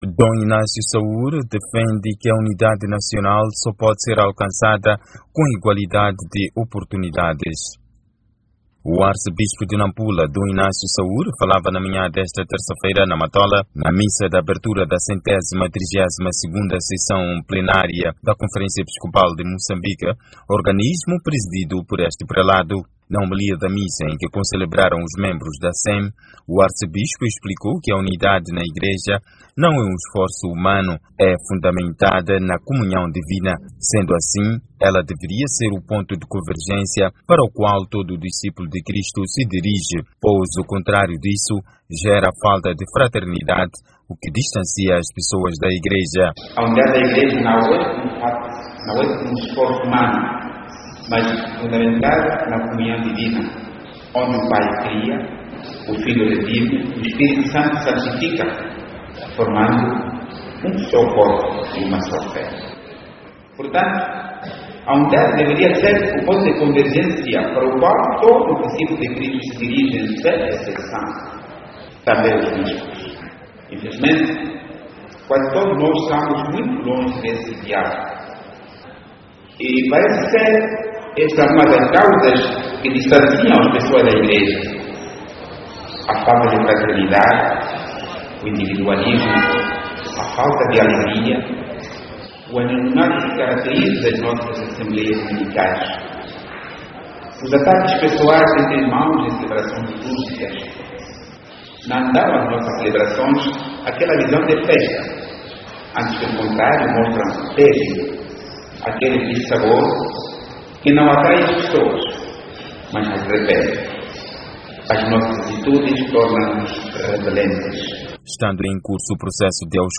O Arcebispo de Nampula, Dom Inácio Saúre falava na manhã desta Terça-feira, 14 de Abril, na Matola, na missa de abertura da 132º Sessão Plenária da Conferência Episcopal de Moçambique (CEM), organismo presidido por este prelado.